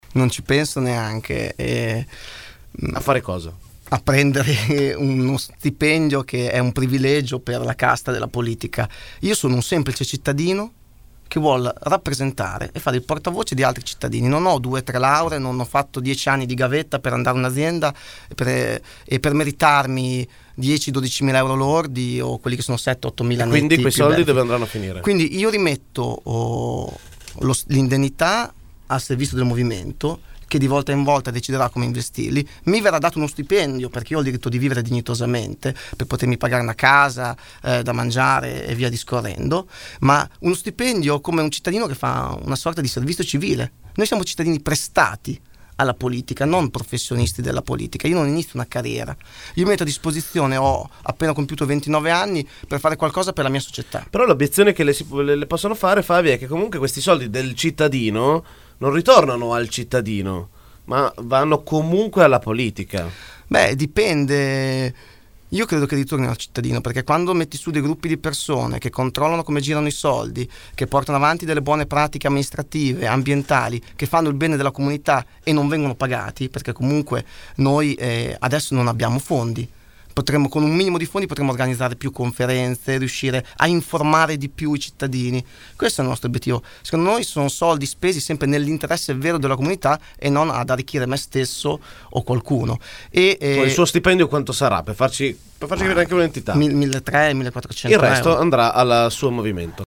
Ecco una sintesi dell’intervista andata in onda questa mattina.